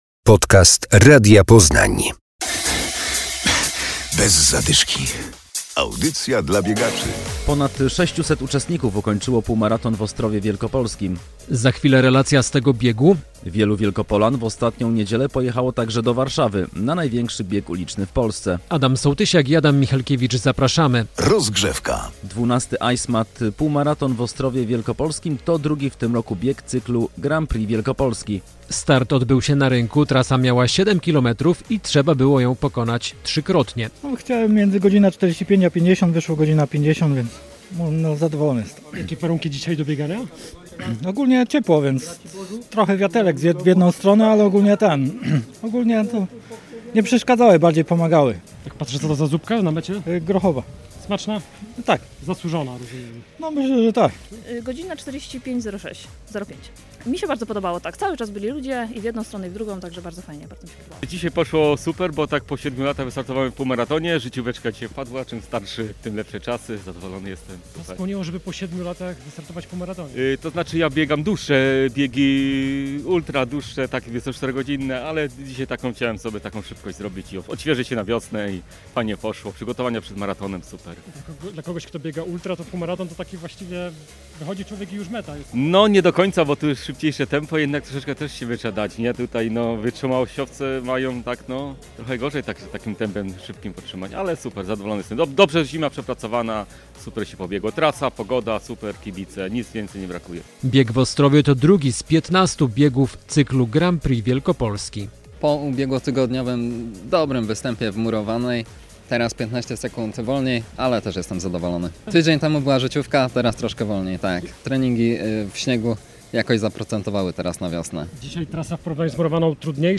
Relacja z półmaratonu w Ostrowie Wlkp.